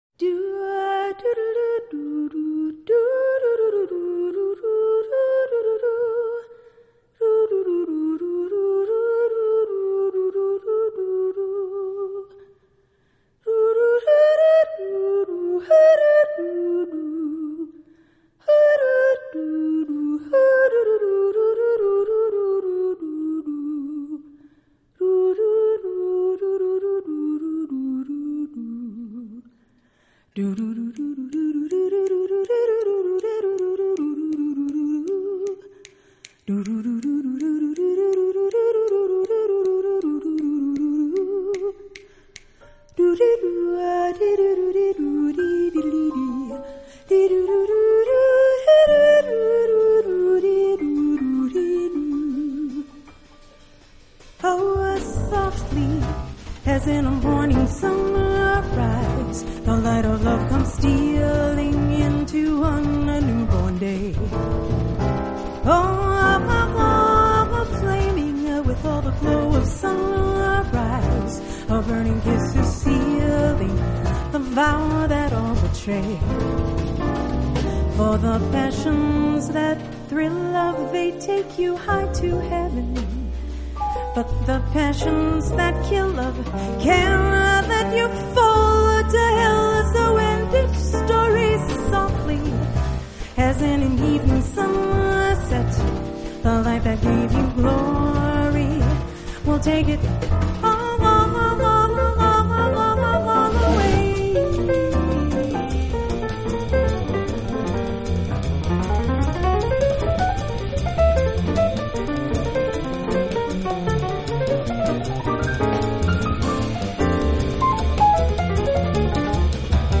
音樂類別 ： 爵士演唱